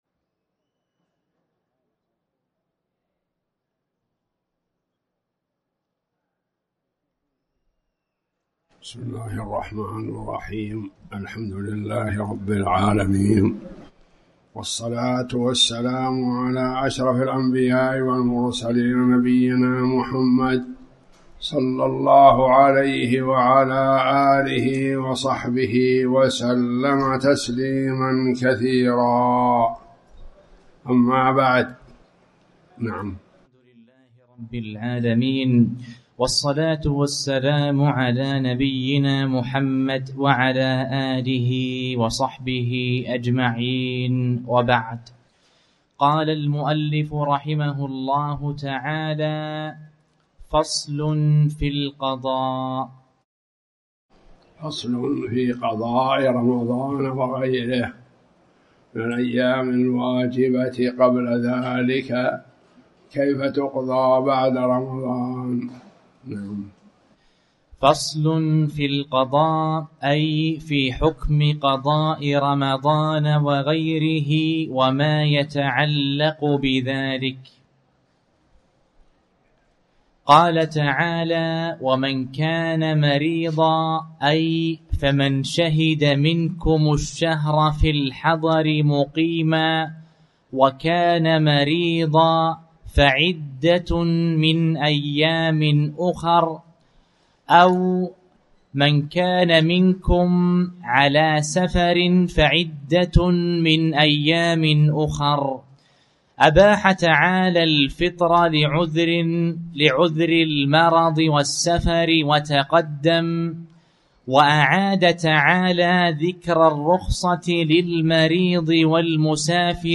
تاريخ النشر ٢٣ شوال ١٤٣٩ هـ المكان: المسجد الحرام الشيخ